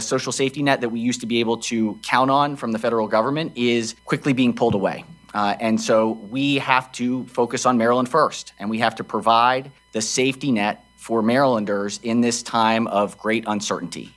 The constant stream of cuts, changes, and plans being changed is creating more issues with the state coming up with a solid budget plan for 2026.  Senate President Bill Ferguson says the help from the federal government is not reliable now…